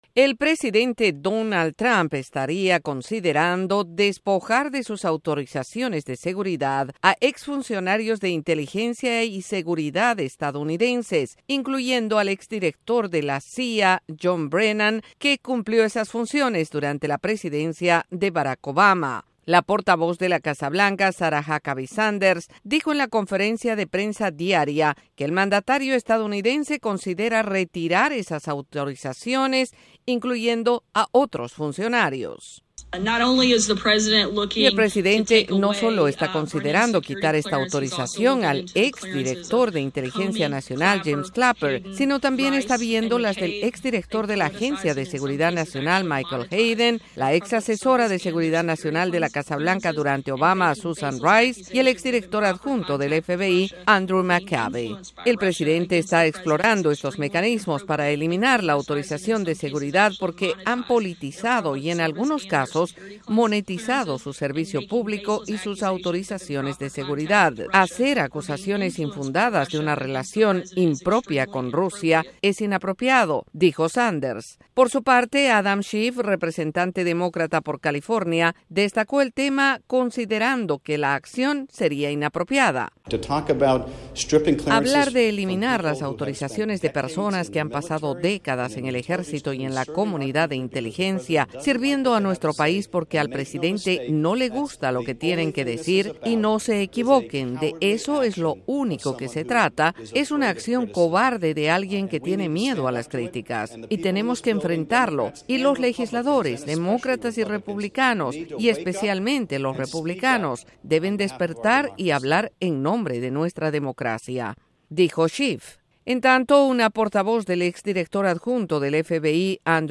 El presidente Donald Trump analiza retirar las autorizaciones de seguridad a ciertos funcionarios. Desde la Voz de América en Washington DC informa